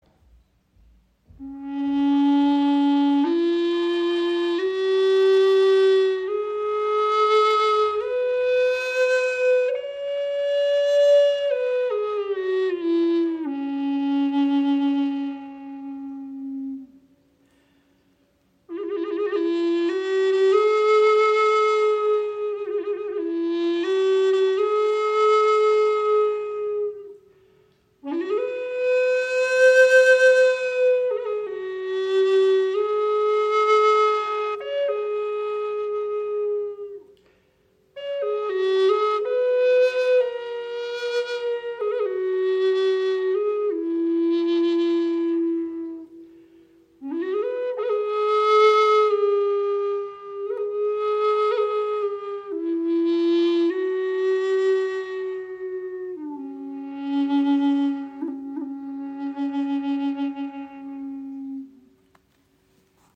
Gebetsflöte in tiefem D - 432 Hz
• Icon Bass Gebetsflöte in tiefem D mit 432 Hz
• Icon 66 cm lang, 6 Grifflöcher
Sie schenkt Dir ein wundervolles Fibrato, kann als Soloinstrument gespielt werden oder als weiche Untermahlung Deiner Musik.